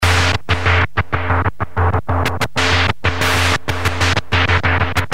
moogspits.mp3